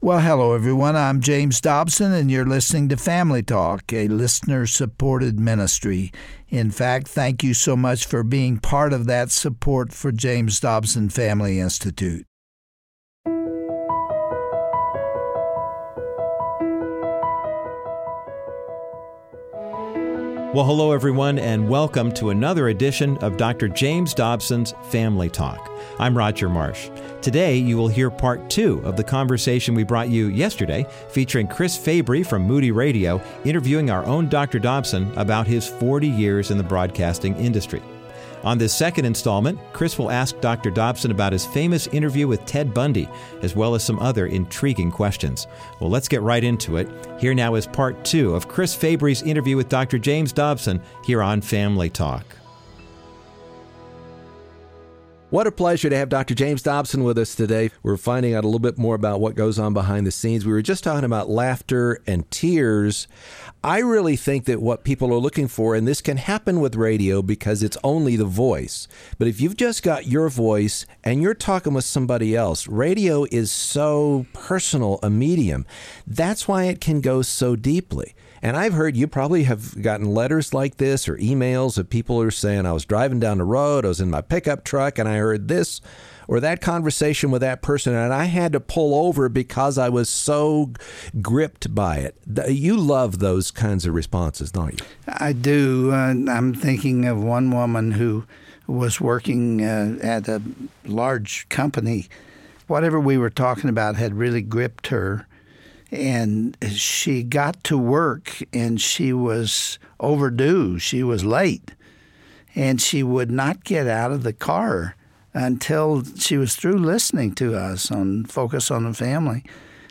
But, on today’s edition of Family Talk, radio host Chris Fabry turns the table and interviews Dr. Dobson. We learn how he regrets not having had the opportunity to interview Francis Schaeffer or Billy Graham. He also shares about his transition from Focus on the Family and his memorable interview with serial killer Ted Bundy, who warned parents to protect their kids from pornography.